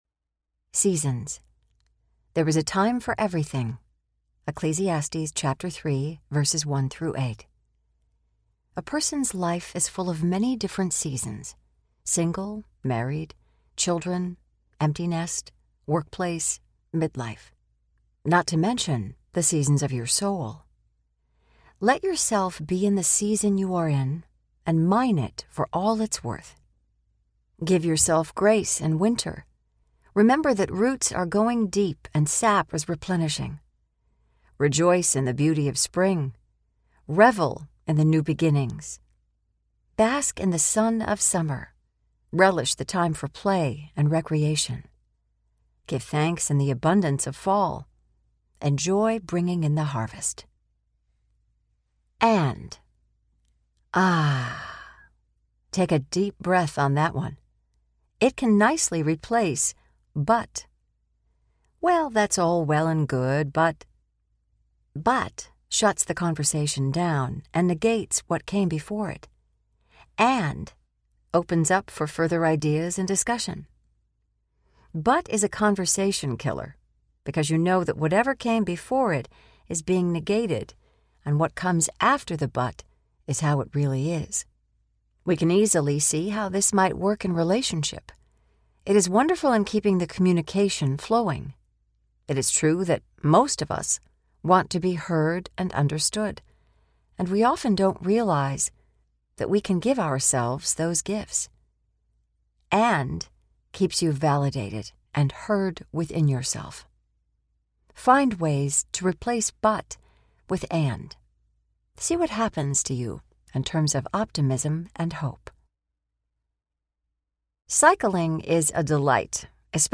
Narrator
6.7 Hrs. – Unabridged